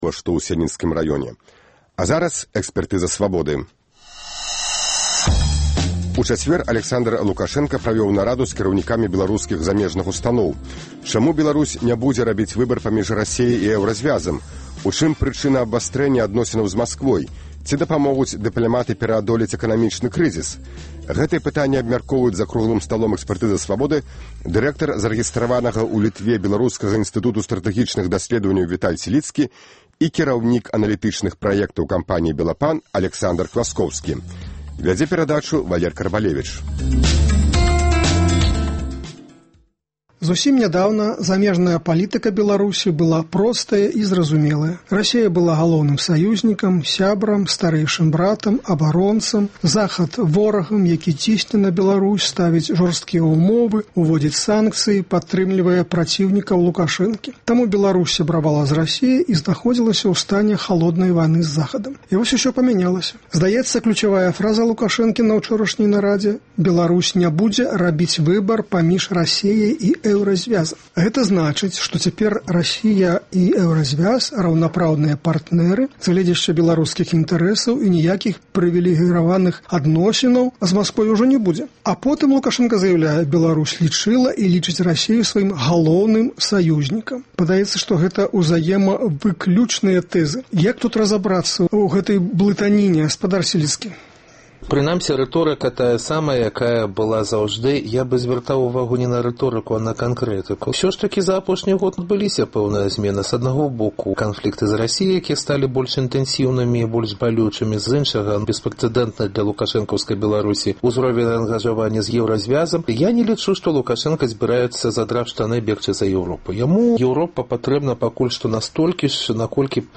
Чаму Беларусь ня будзе рабіць выбар паміж Расеяй і Эўразьвязам? У чым прычына абвастрэньня адносінаў з Масквой? Ці дапамогуць дыпляматы пераадолець эканамічны крызыс? Гэтыя пытаньні абмяркоўваюць за круглым сталом